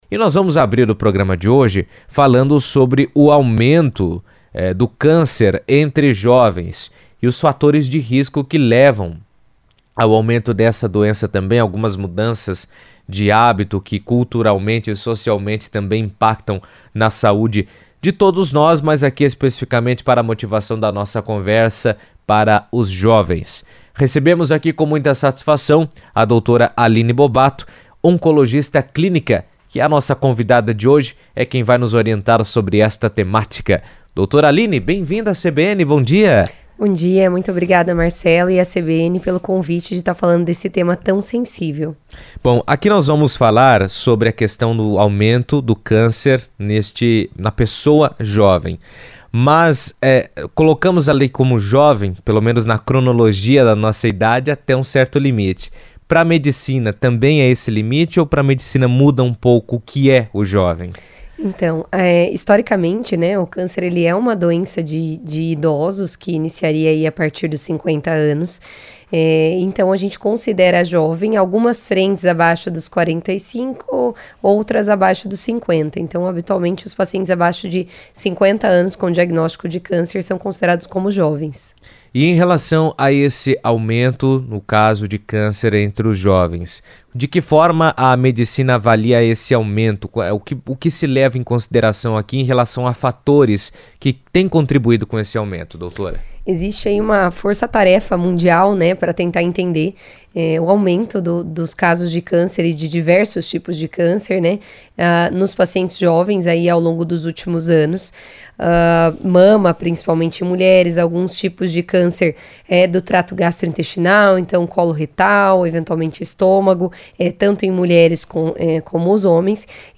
entrevista à CBN